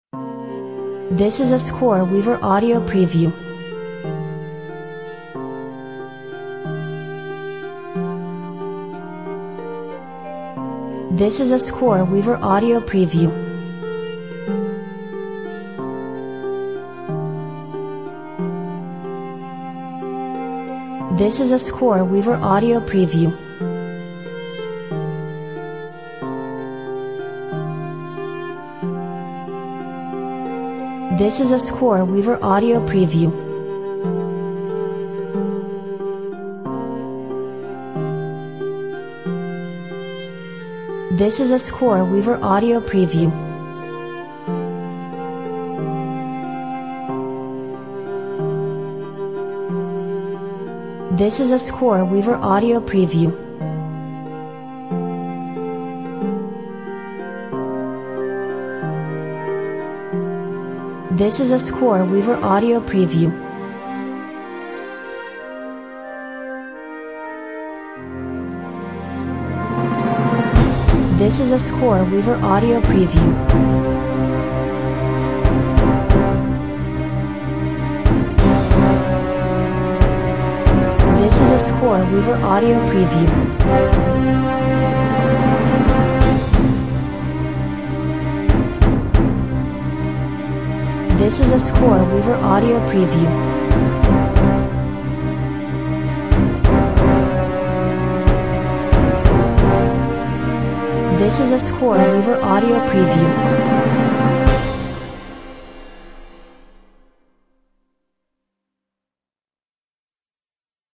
Emotionally charged Fantasy Soundtrack!